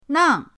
nang4.mp3